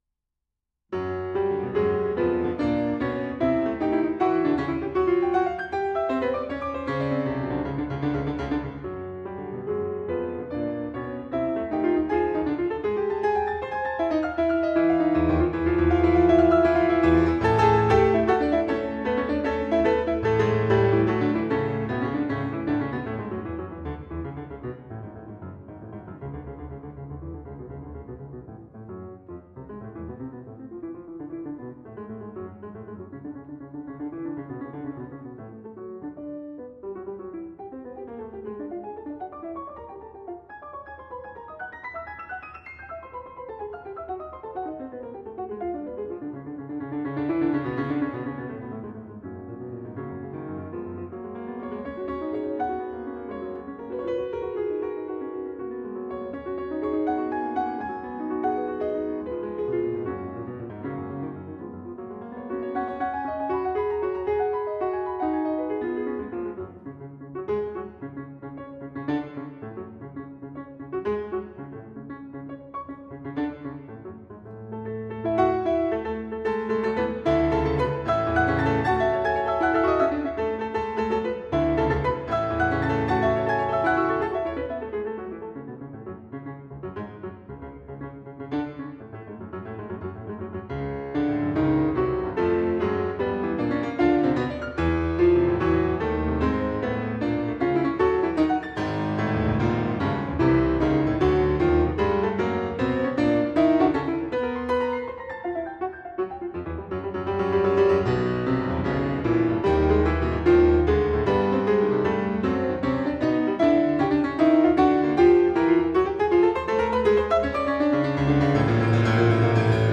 piano sonatas
neo-classical
We’ve selected the third movement, marked Presto.